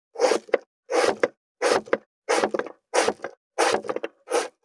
570肉切りナイフ,まな板の上,包丁,
効果音厨房/台所/レストラン/kitchen食器食材